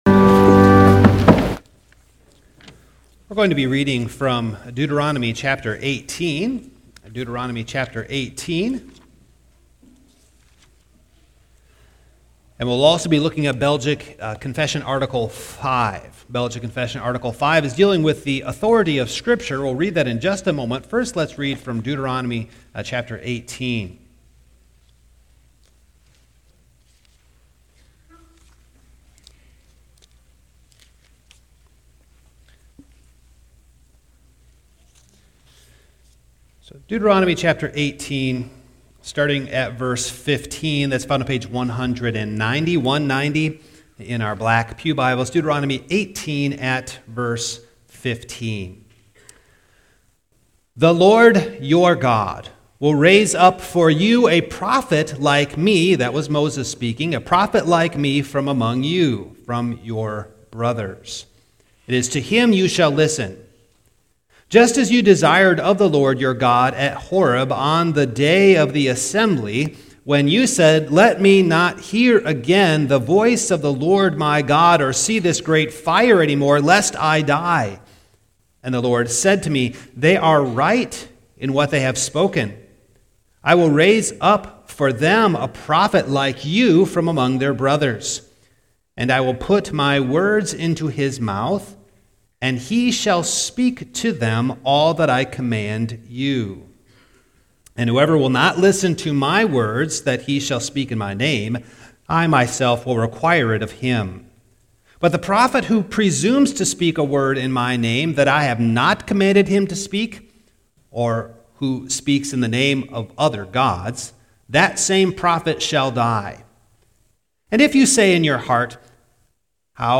Passage: Dt. 18:15-22 Service Type: Morning